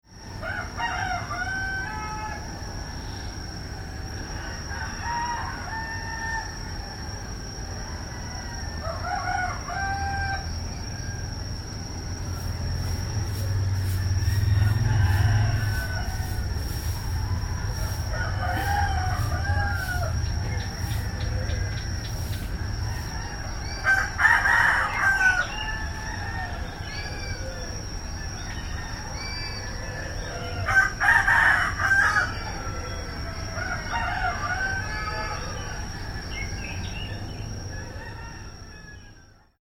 Alle Sounds gibt es in 2 Formaten, im RealAudio-Format in ausreichender Qualität und im MP3-Format in sehr guter Qualität.
3 Morgenstimmung in Ubud
morgenstimmung.mp3